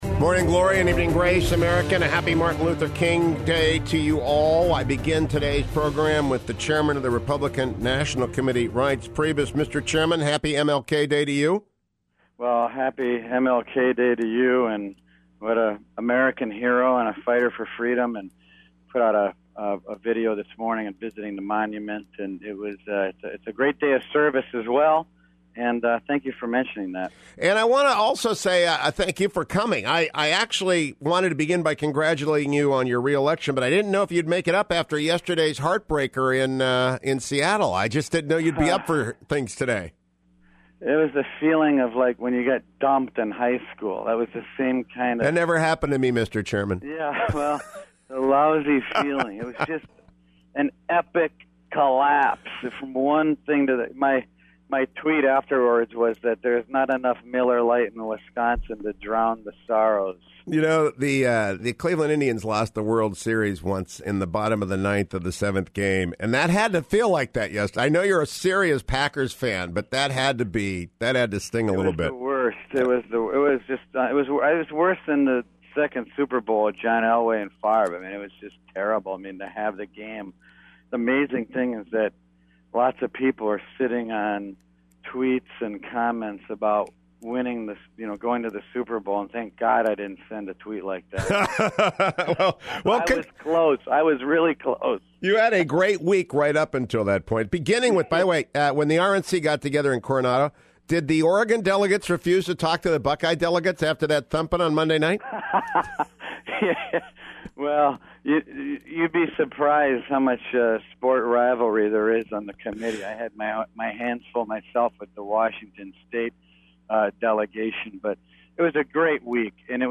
RNC Chair Reince Priebus opened my Martin Luther King Day program talking about the new debate schedule and format for the 2016 GOP primaries.